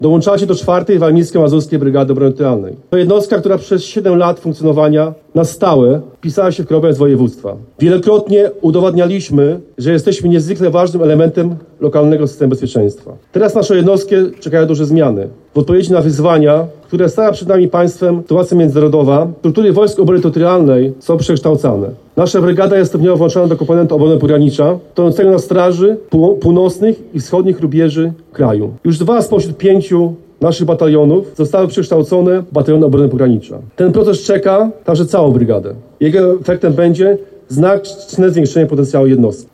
Blisko 50 żołnierzy Wojsk Obrony Terytorialnej złożyło przysięgę wojskową. Uroczystość odbyła się w miniony weekend w Braniewie.